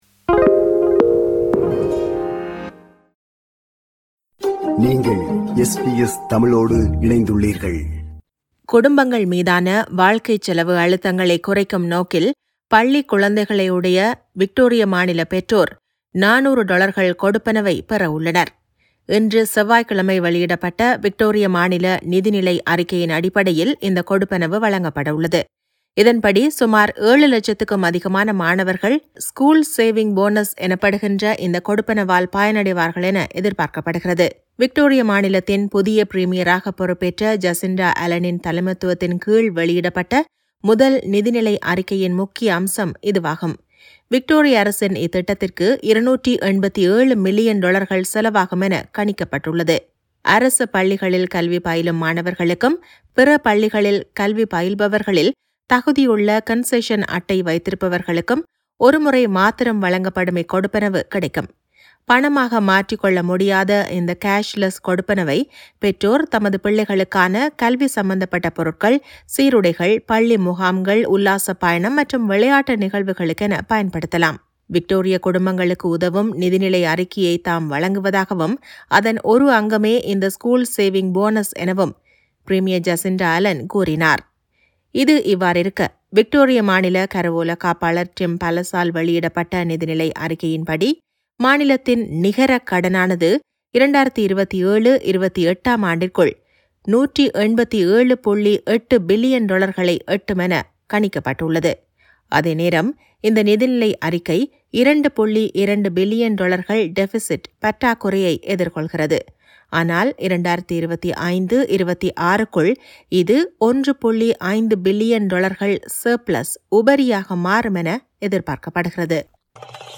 குடும்பங்கள் மீதான வாழ்க்கைச் செலவு அழுத்தங்களைக் குறைக்கும் நோக்கில், பள்ளிக் குழந்தைகளையுடைய விக்டோரிய மாநில பெற்றோர் 400 டொலர்கள் கொடுப்பனவைப் பெறவுள்ளனர். இதுகுறித்த செய்தியைத் தருகிறார்